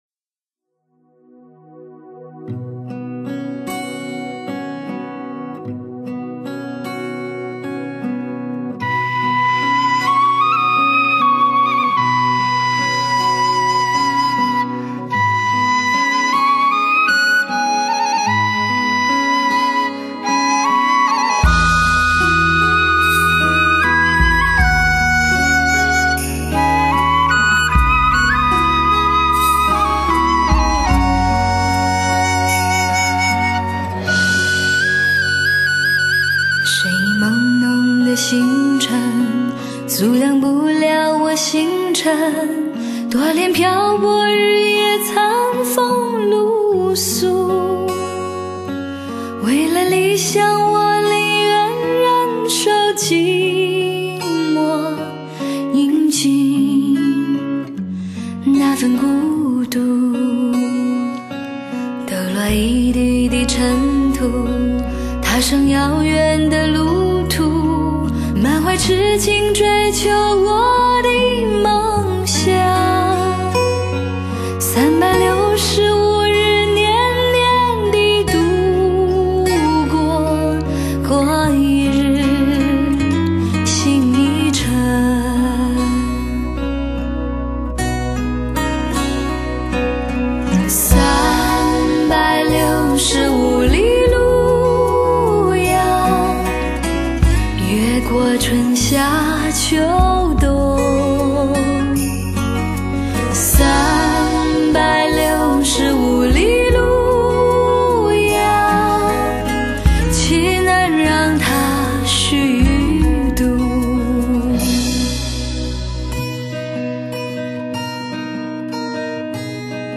多元化的编曲，更现经典名曲的风华与感动。